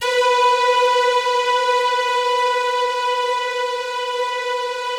BIGORK.B3 -L.wav